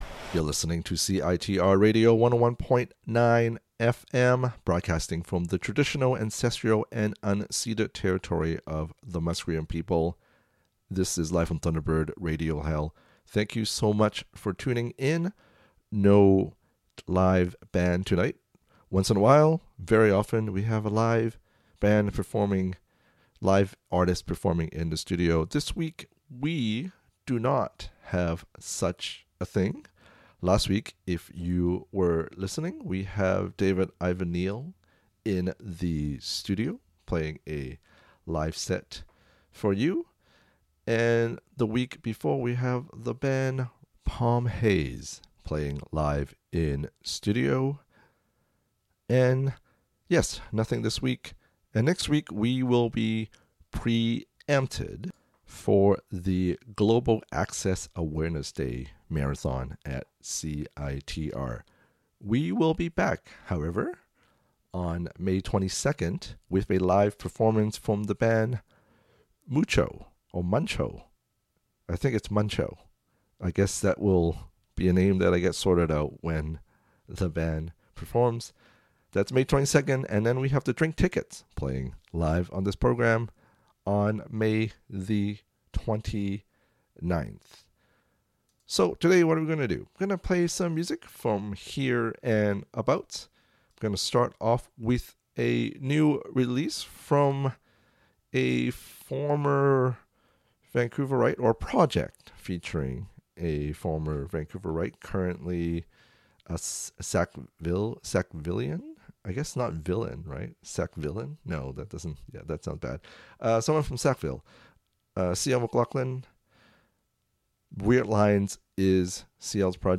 Hello, here are some songs for your enjoyment.